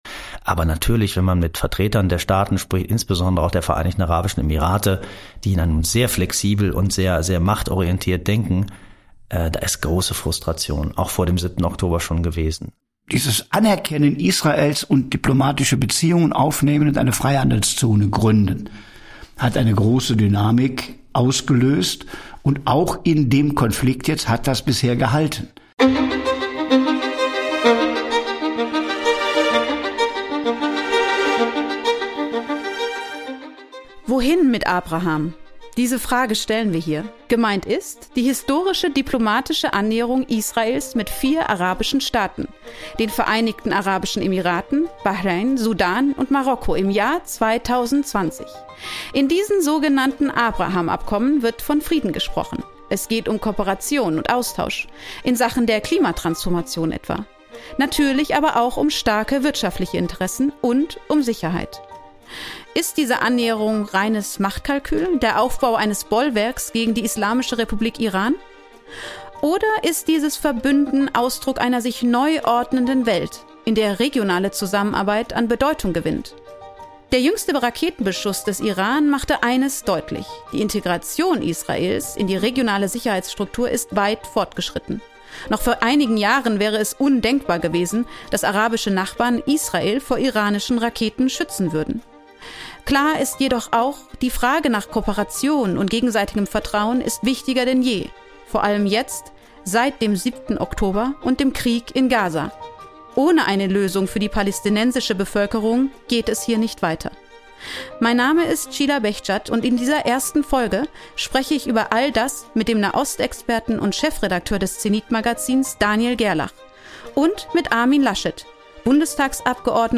im Gespräch über den aktuellen Stand der Abraham Accords.